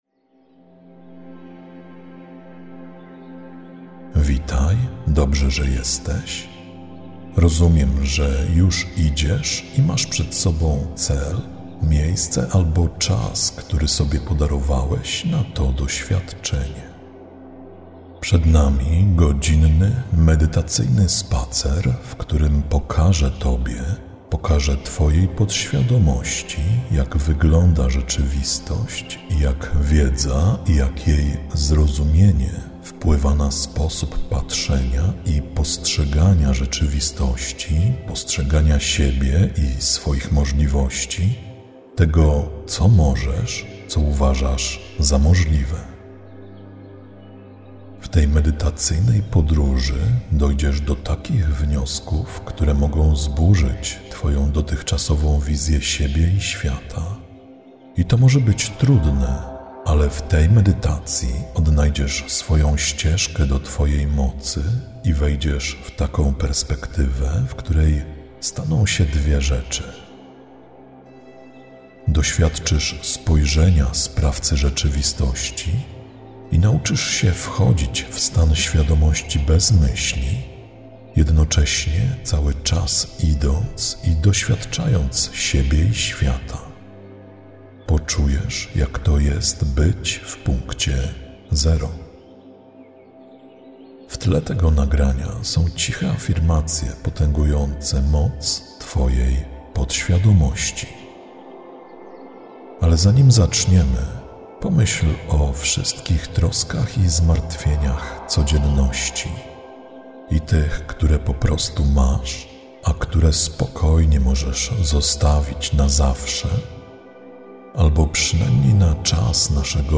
Zawiera lektora: Tak